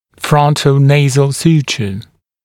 [ˌfrʌntə’neɪz(ə)l ‘s(j)uːʧə][ˌфрантэ’нэйз(э)л ‘с(й)у:чэ]носолобный шов